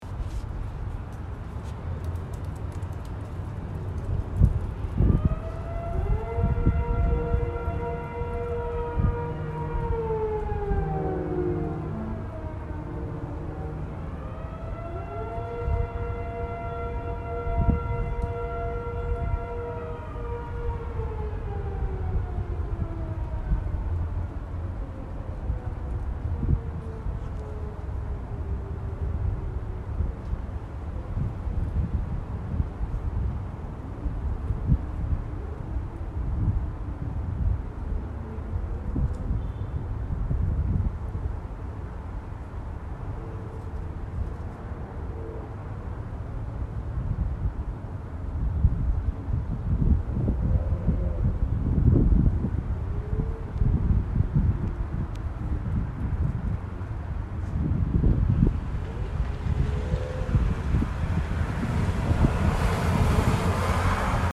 • Wind blowing through the trees and against the phone speaker
• Leaves rustling against the sidewalk and street
• Emergency sirens
• Low horn honking
• Car beeping after being locked in the distance
• Motorcycle revving
• Car driving past, wheels against the street, and engine revving